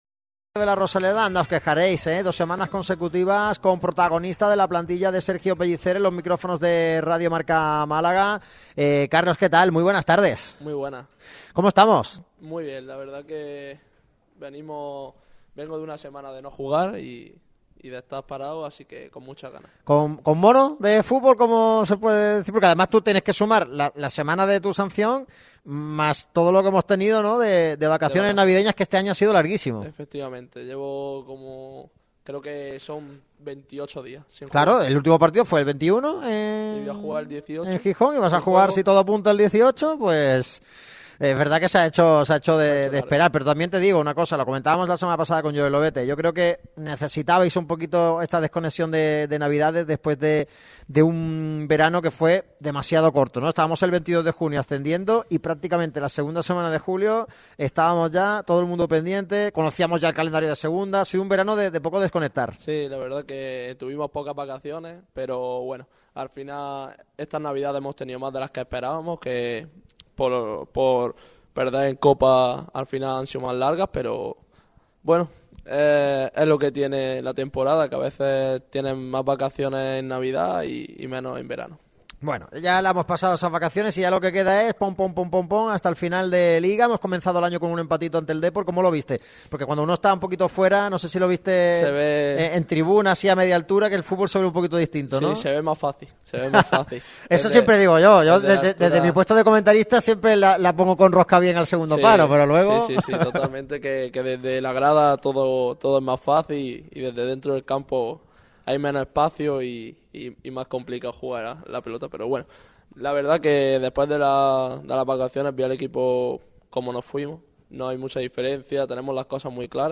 Así fue la entrevista con Carlos Puga en Radio MARCA Málaga.
Segunda semana consecutiva con protagonista del Málaga CF en los micrófonos de Radio MARCA Málaga.